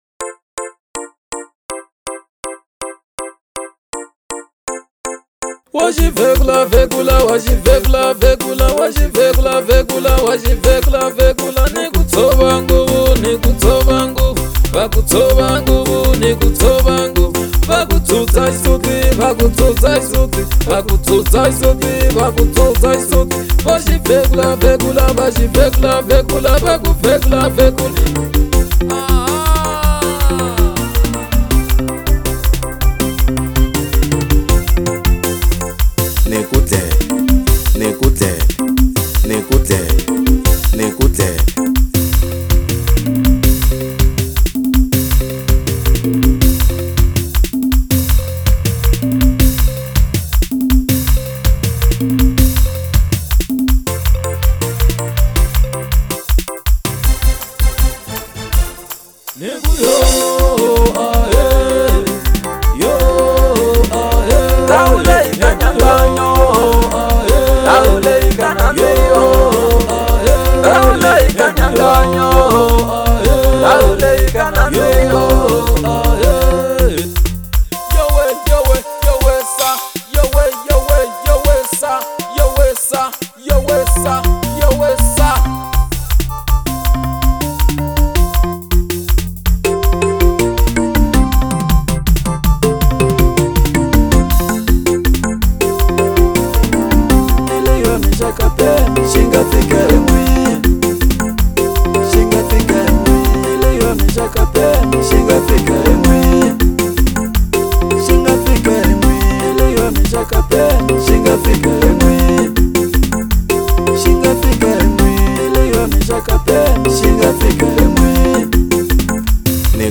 Xitsonga